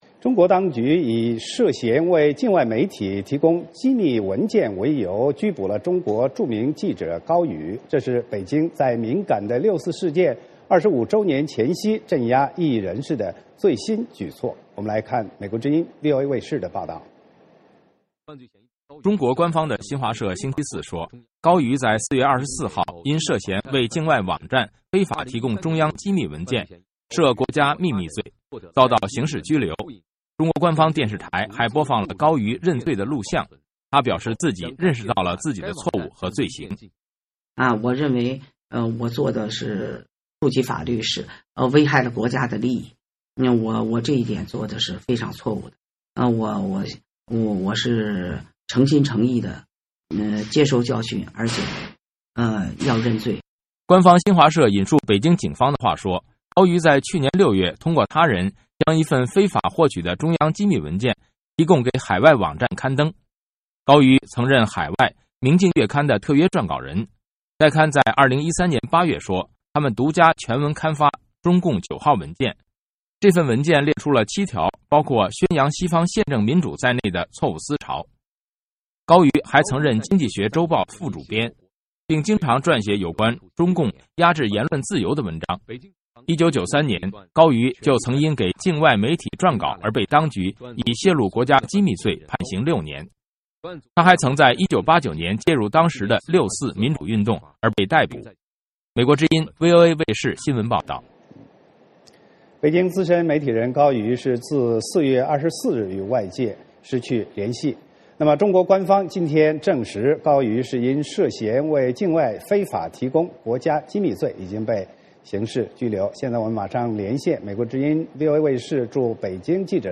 VOA连线：中国著名记者高瑜遭当局刑事拘留